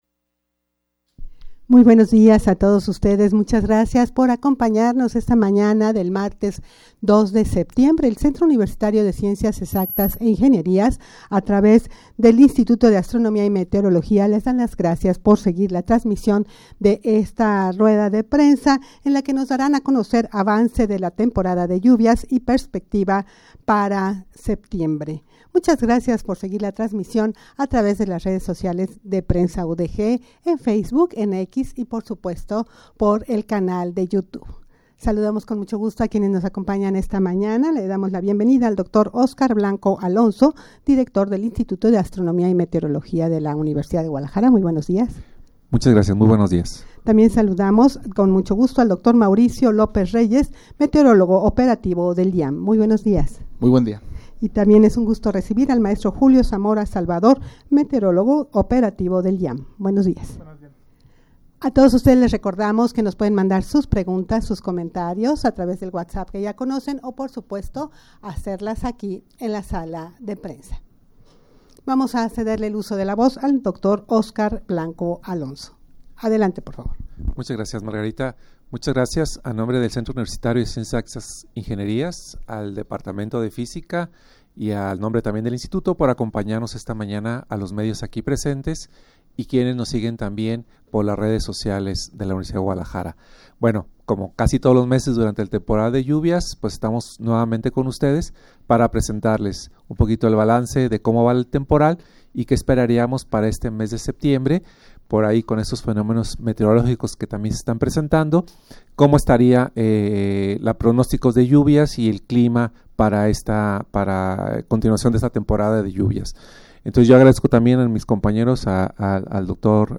Audio de la Rueda Prensa
rueda-de-prensa-avance-de-la-temporada-de-lluvias-y-perspectiva-para-septiembre.mp3